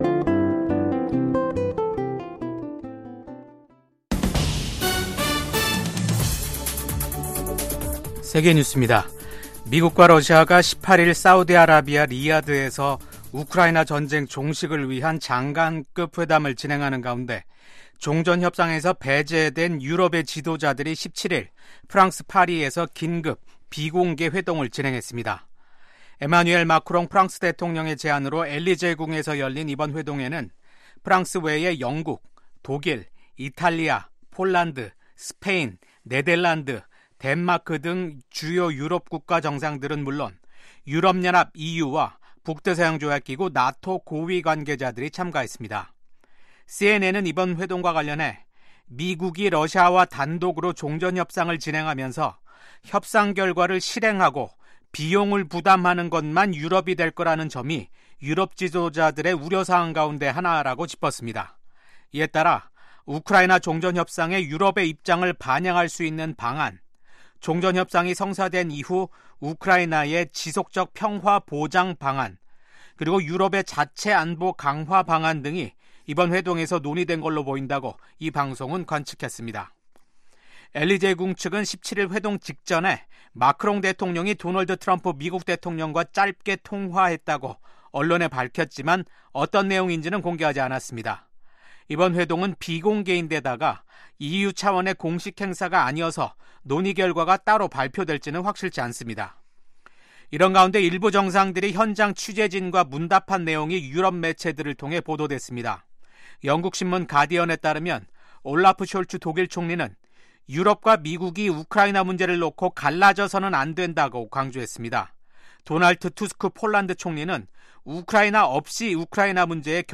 VOA 한국어 아침 뉴스 프로그램 '워싱턴 뉴스 광장'입니다. 미국과 한국, 일본의 외교장관들이 북한의 완전한 비핵화에 대한 확고한 의지를 재확인했습니다. 15일 열린 미한일 외교장관 회의에서 북한의 완전한 비핵화 목표를 확인한 데 대해 도널드 트럼프 행정부가 투트랙 대북전략을 구사할 것이라는 관측이 나왔습니다. 미국의 전술핵무기를 한반도에 배치할 경우 위기 상황에서 생존할 가능성이 낮다고 전 미국 국방부 차관보가 밝혔습니다.